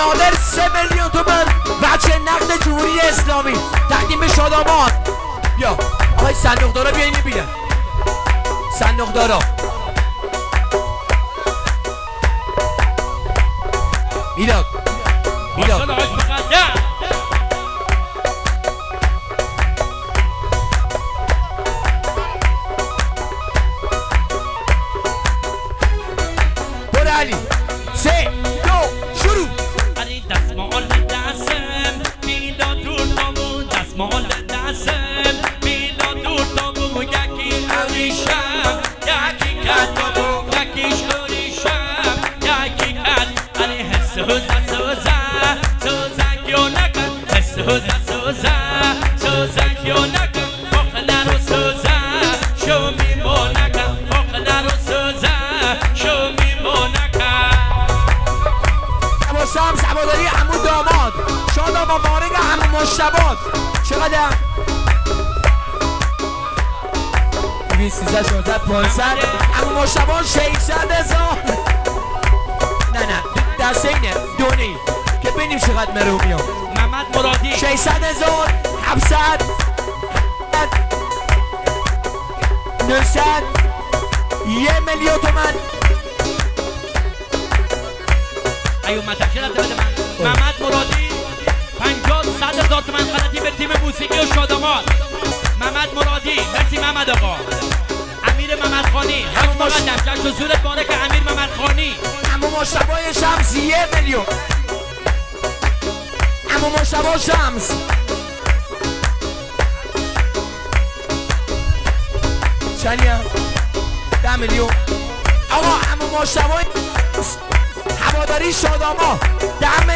ترانه محلی لری